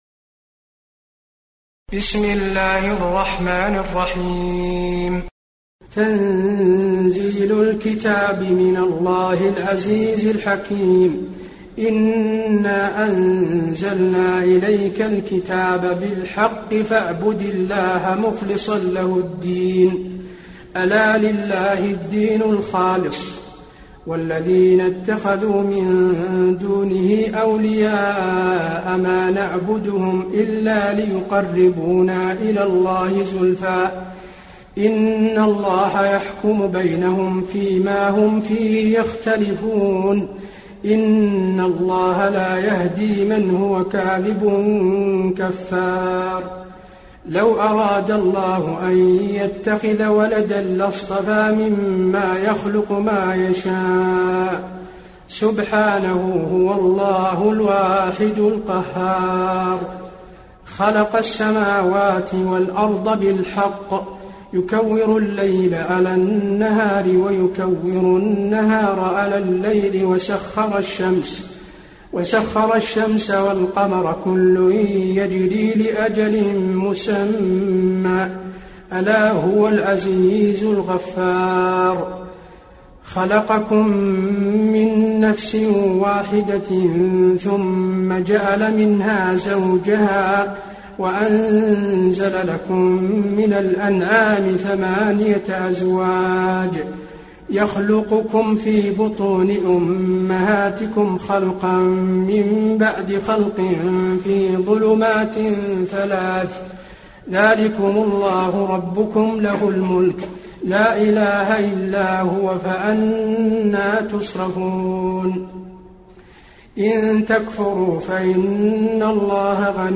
المكان: المسجد النبوي الزمر